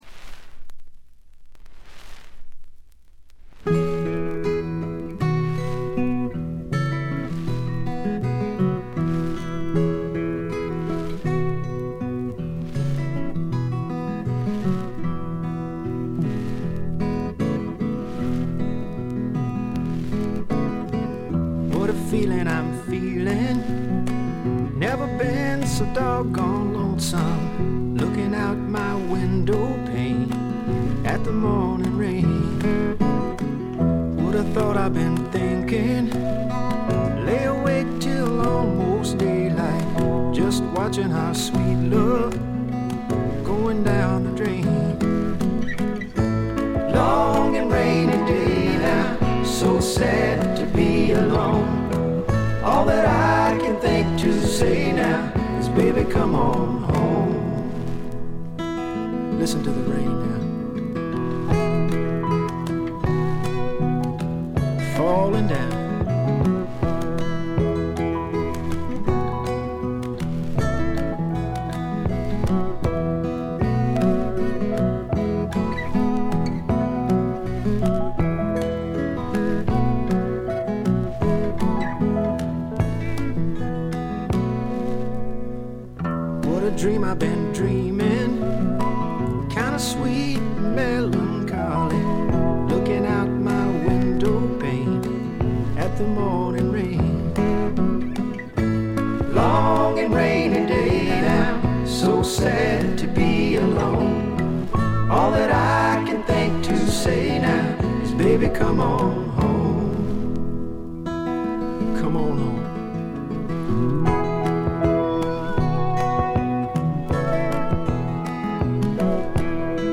両面とも1曲目から2曲目にかけて「ザー」という周回ノイズ。これ以外は軽微なチリプチ程度です。
本来カントリー畑のソングライターですが本作では曲が良いのはもちろん渋いヴォーカルも披露しております。
試聴曲は現品からの取り込み音源です。
Acoustic Guitar, Banjo, Drums, Guitar [Bottleneck]
Piano, Organ, Bass